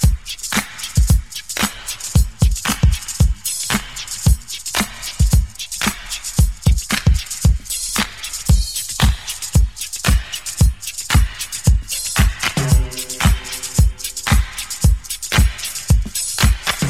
• 113 Bpm Drum Groove C Key.wav
Free drum beat - kick tuned to the C note. Loudest frequency: 2804Hz
113-bpm-drum-groove-c-key-LME.wav